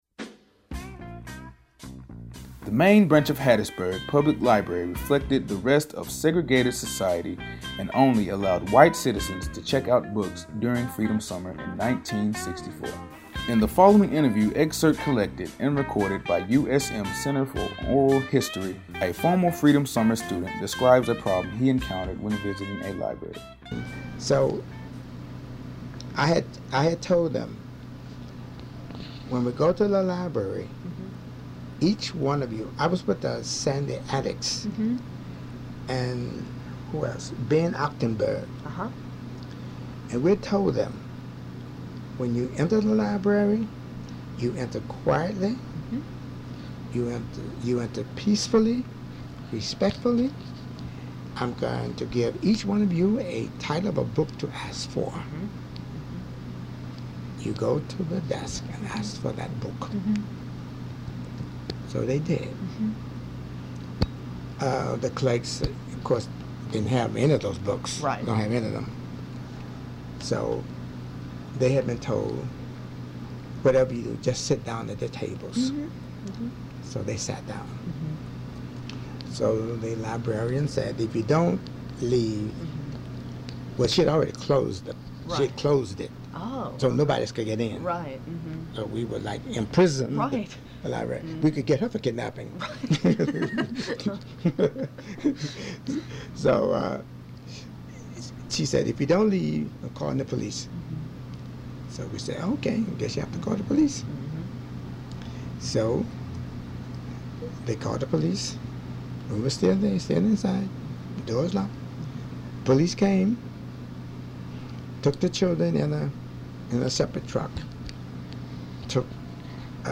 He recounts the story on the audio for this stop.